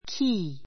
key 中 A1 kíː キ ー 名詞 ❶ 鍵 かぎ ⦣ 「（問題を解く・目的を達成する）方法」の意味でも使う. turn a key in the lock 関連語 turn a key in the lock 錠 じょう に鍵を差して回す, 鍵をかける[開ける] She had no key to the door.